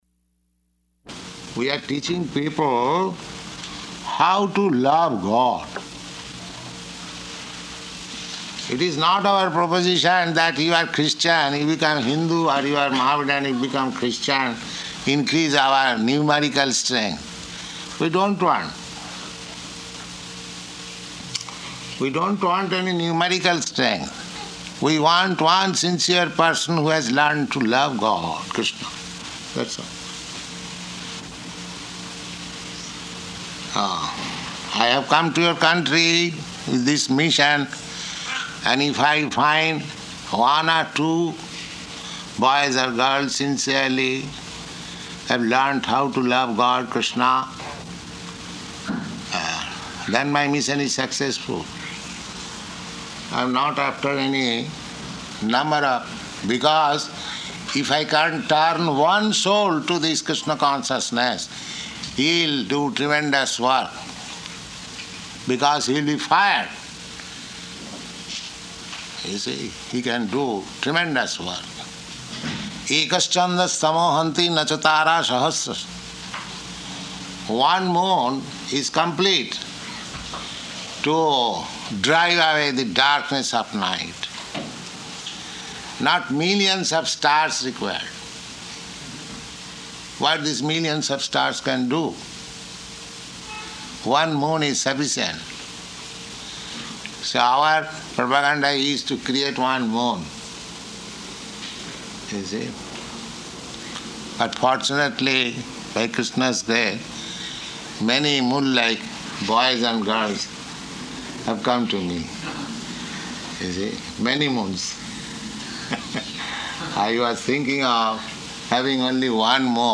Lecture
Lecture --:-- --:-- Type: Lectures and Addresses Dated: April 12th 1969 Location: New York Audio file: 690412LE-NEW_YORK.mp3 Prabhupāda: We are teaching people how to love God.